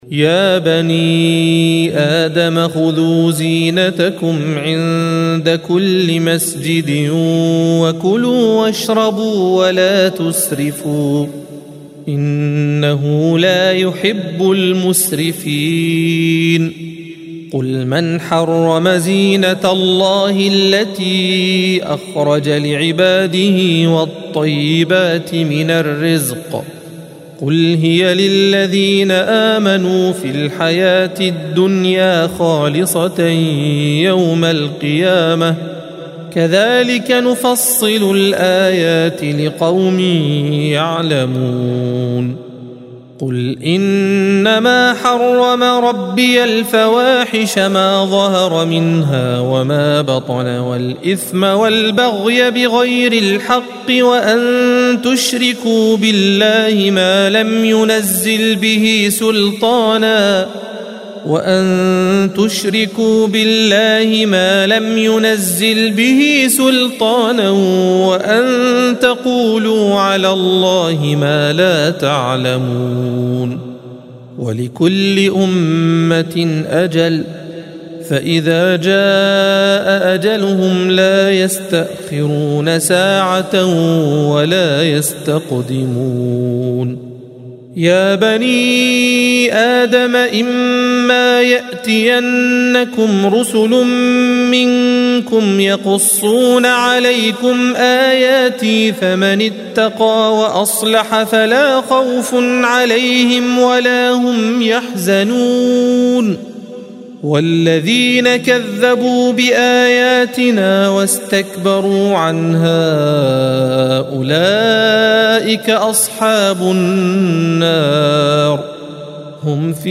الصفحة 154 - القارئ